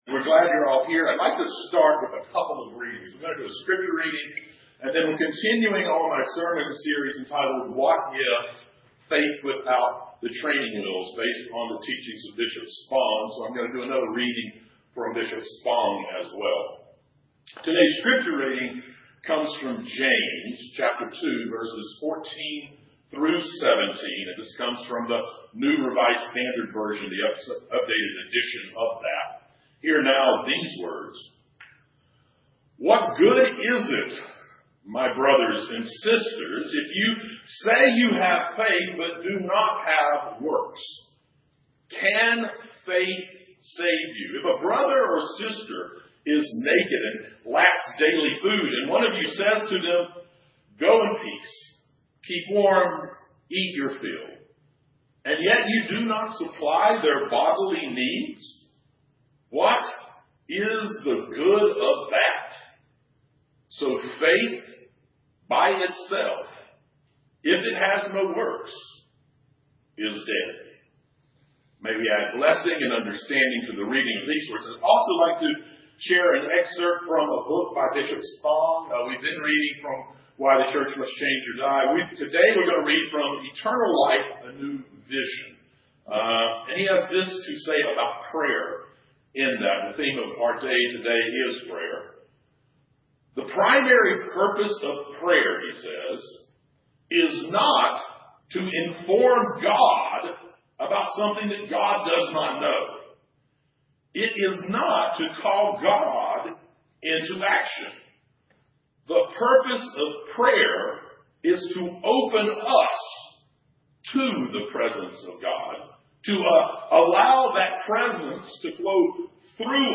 Sermon Series: What if? Faith Beyond the Training Wheels.